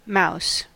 mouse (4).mp3